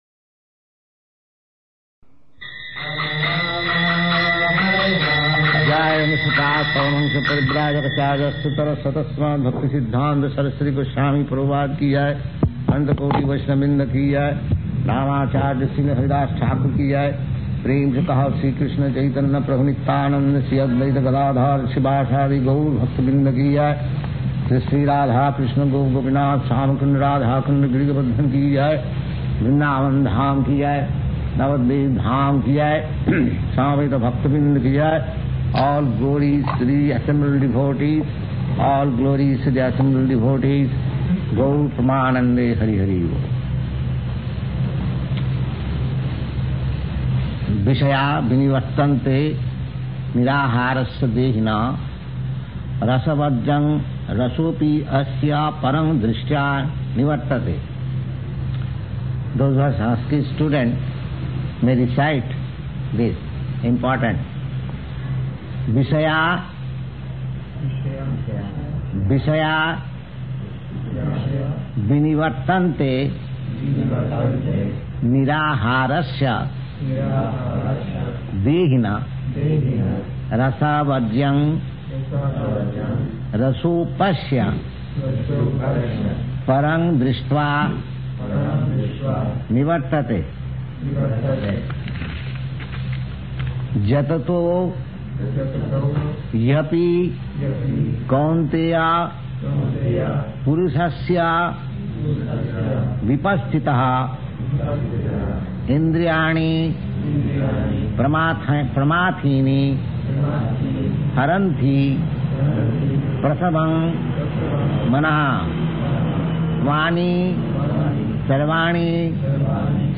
Bhagavad-gītā 2.59–69 --:-- --:-- Type: Bhagavad-gita Dated: April 18th 1966 Location: New York Audio file: 660418BG-NEW_YORK.mp3 [ kīrtana ] Prabhupāda: [ prema-dhvani ] Gaura-Premanandi.
[recites with students responding through end of verse 61]